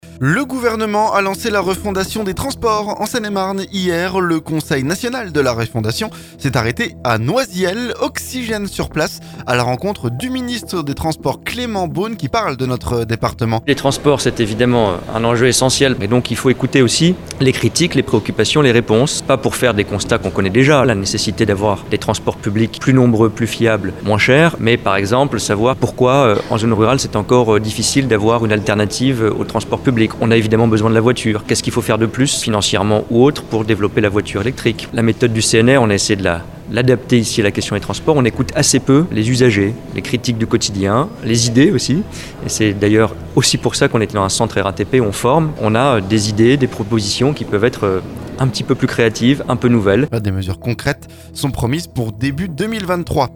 Oxygène sur place à la rencontre du ministre des transports Clément Beaune, qui parle de notre département.